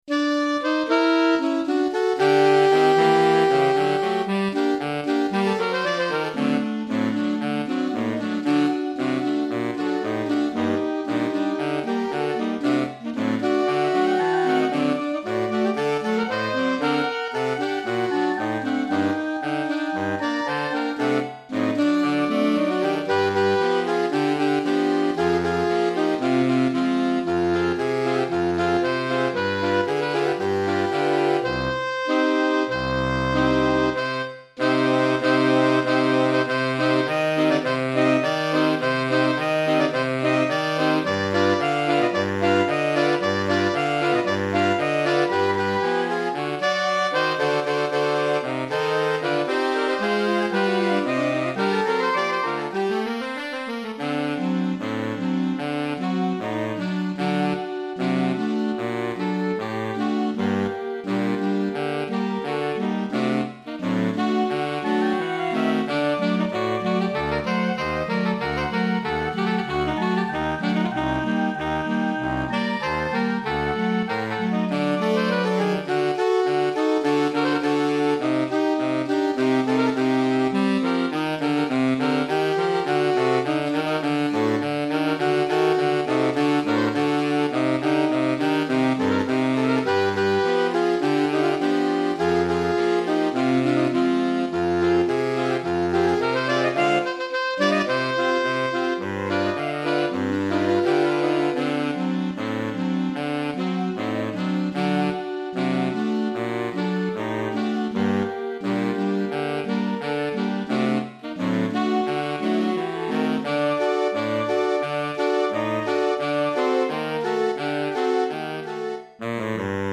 5 Saxophones